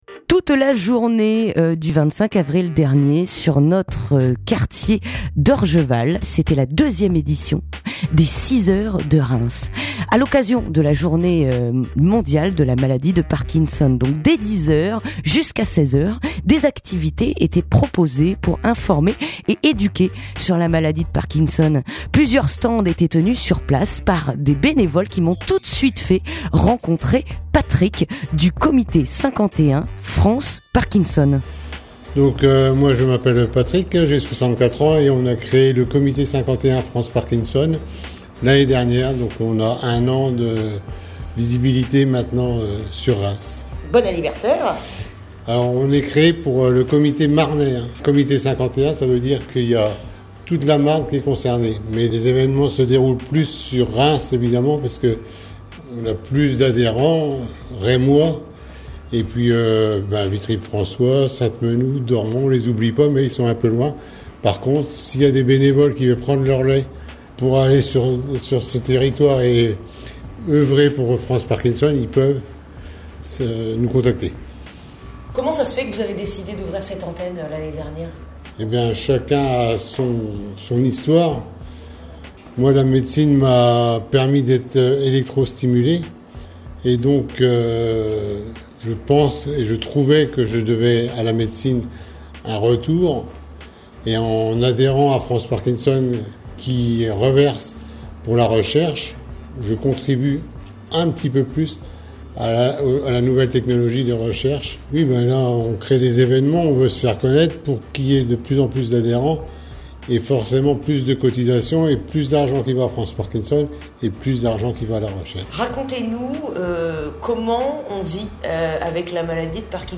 Reportage à Orgeval (15:29)